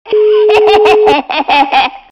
Ведьма - Смех Звук Звуки Відьма - сміх
При прослушивании Ведьма - Смех качество понижено и присутствуют гудки.
Звук Ведьма - Смех